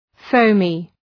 Shkrimi fonetik {‘fəʋmı}